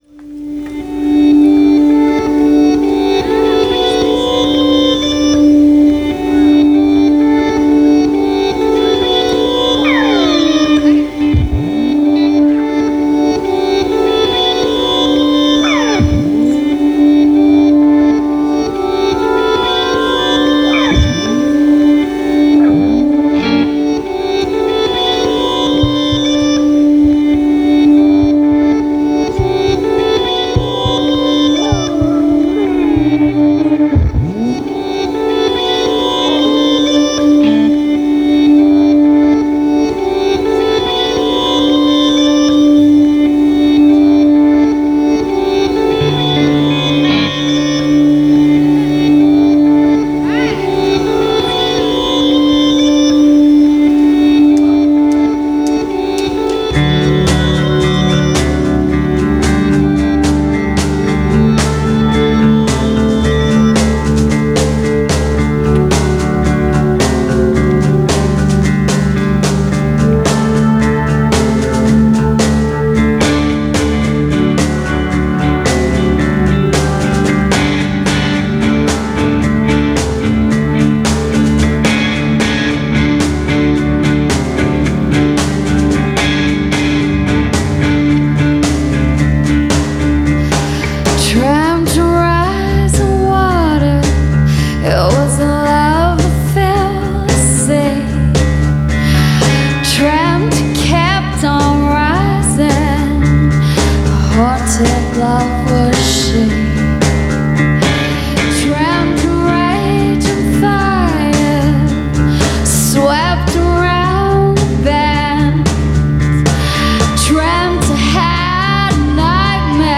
vocals, guitar
bass, keys, vocals
drums/vocals